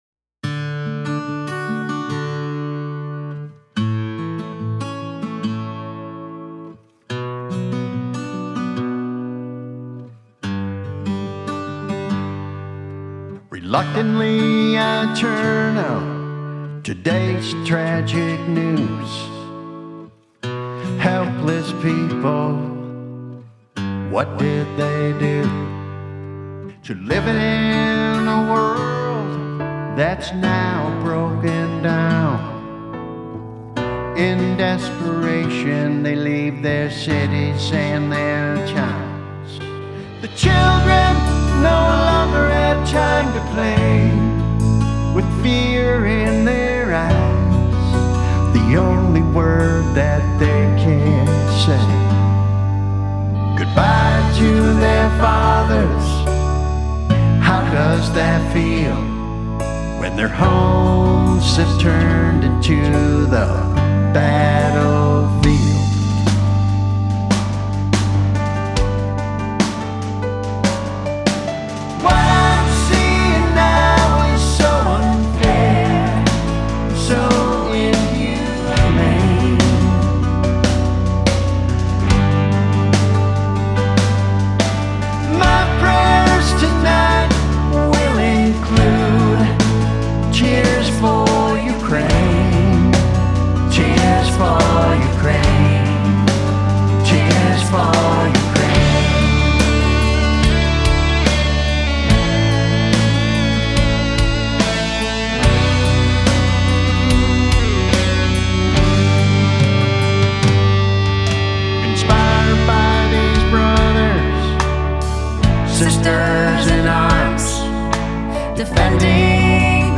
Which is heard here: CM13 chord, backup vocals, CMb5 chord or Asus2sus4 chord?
backup vocals